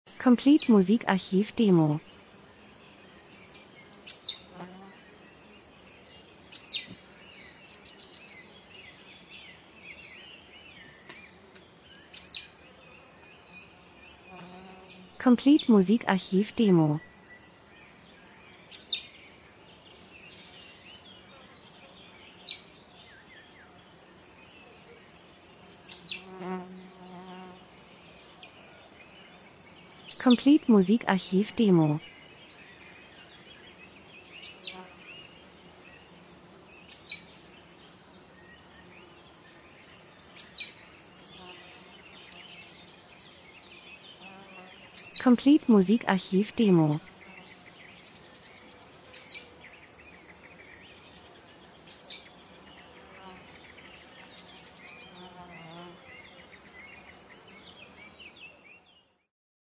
Sommer -Geräusche Soundeffekt Natur Blumenwiese Vögel Insekten 00:59